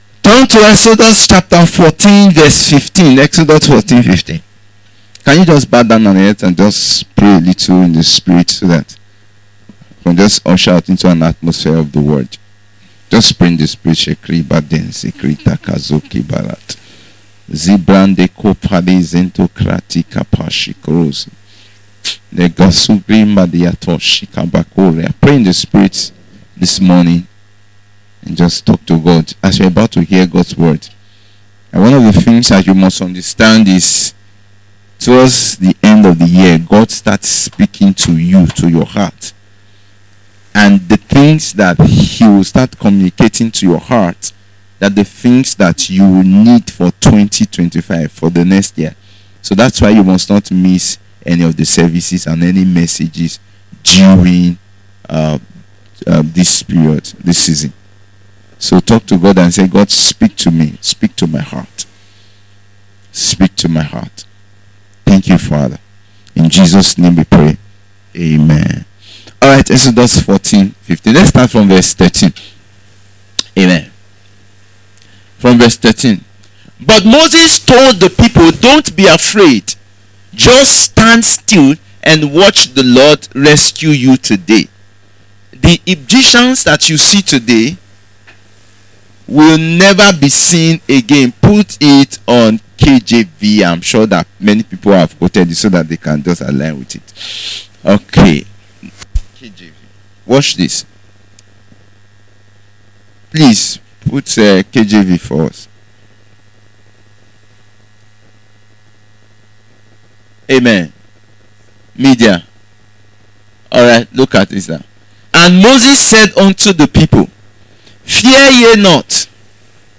Faith Service Type: Revelation Service Don’t stay where you are